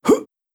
Player_Jump 02.wav